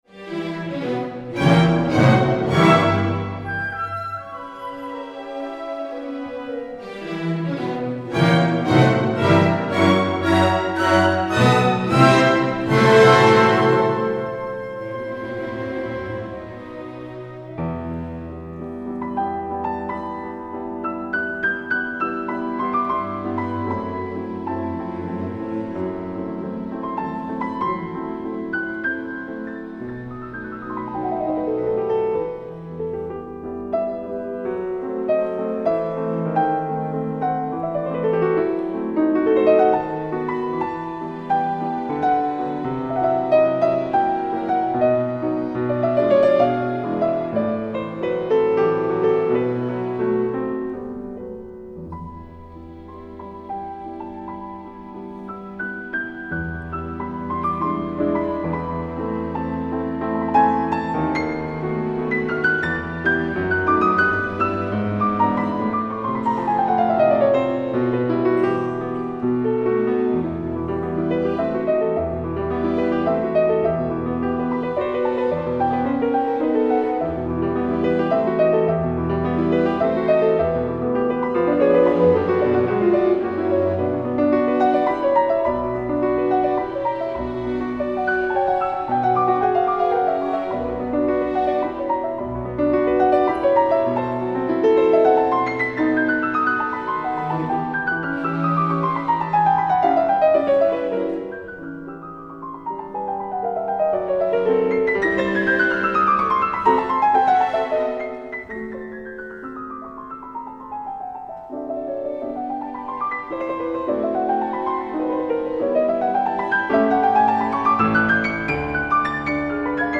2. Frédéric Chopin Klavierkonzert e-Moll op.11 aus Allegro maestoso
(Konzertmitschnitt 14.6.2005 KKL, Luzerner Sinfonieorchester)